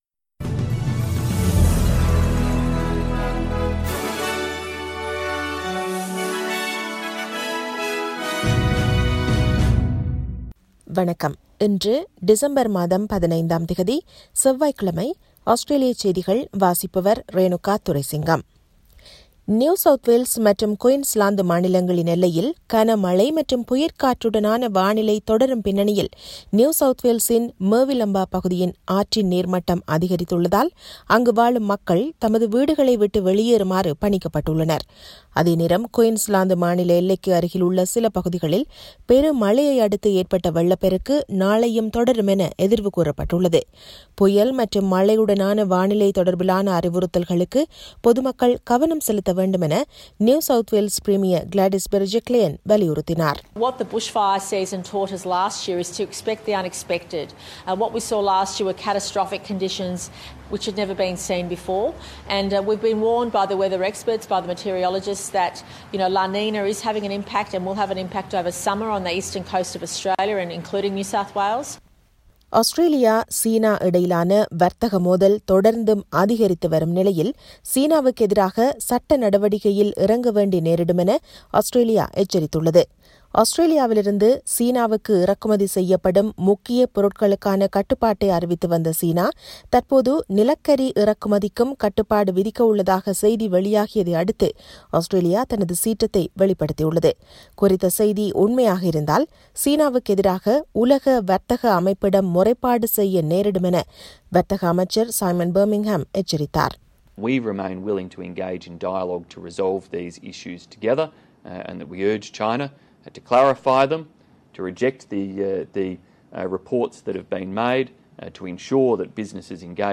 Australian news bulletin for Tuesday 15 December 2020.